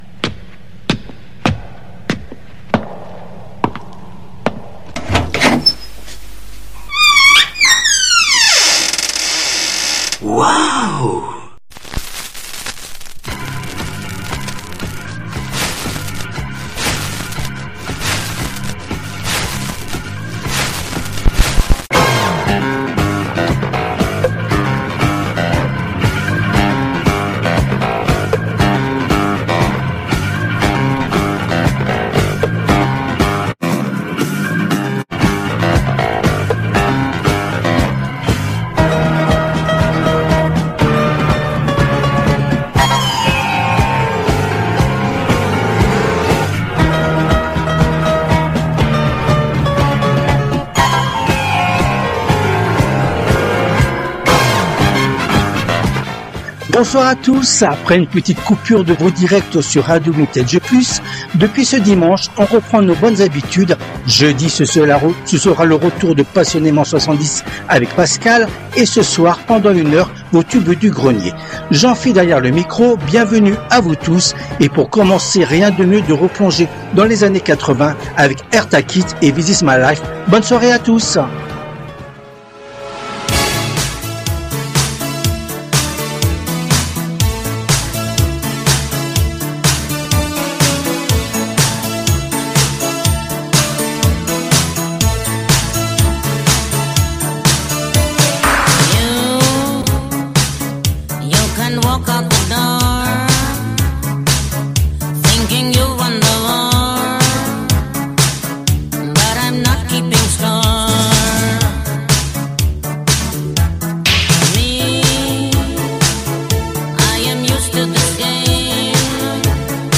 Les TUBES DU GRENIER constituent la première émission hebdomadaire phare de la semaine sur RADIO VINTAGE PLUS et après une pause des directs au printemps, cette édition a été diffusée en direct le mardi 29 avril 2025 à 19h depuis les studios de RADIO RV+ à PARIS .